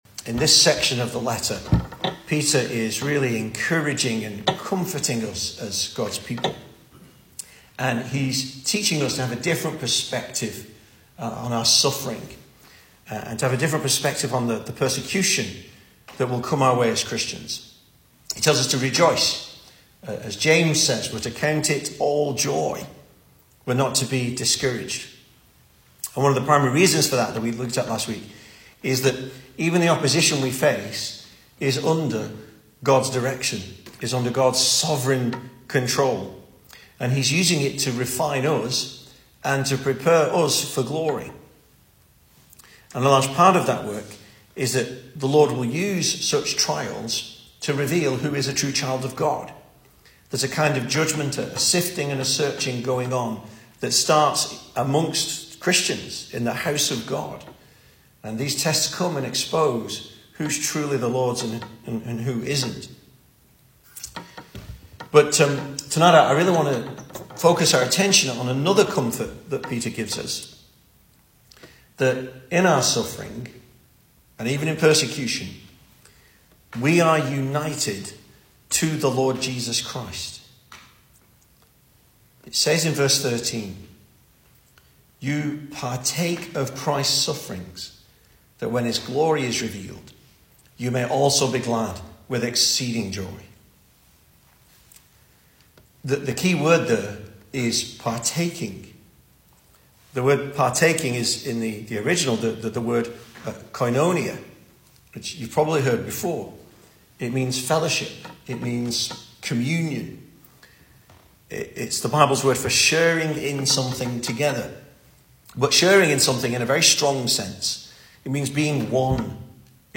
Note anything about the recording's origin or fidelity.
2024 Service Type: Weekday Evening Speaker